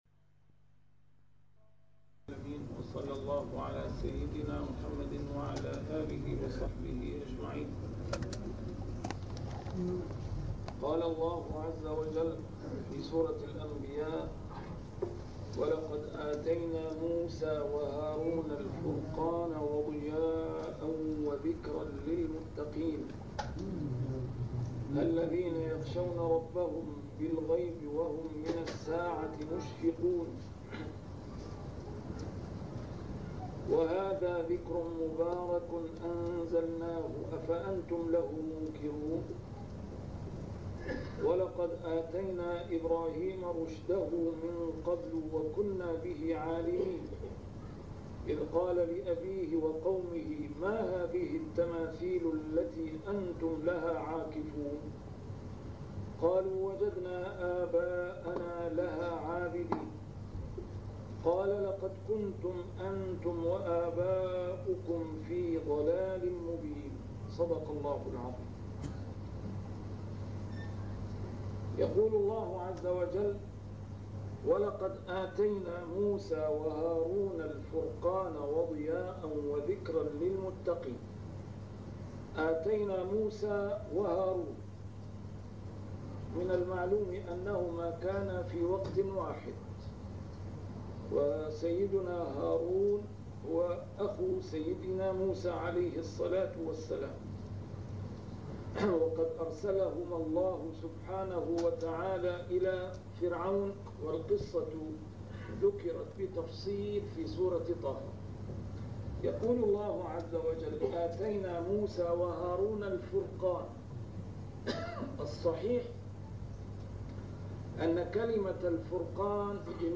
A MARTYR SCHOLAR: IMAM MUHAMMAD SAEED RAMADAN AL-BOUTI - الدروس العلمية - تفسير القرآن الكريم - تسجيل قديم - الدرس 93: الأنبياء 048-050